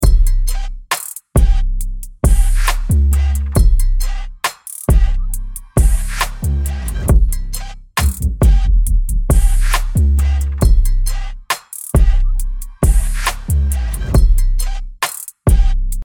Hype Trap Beat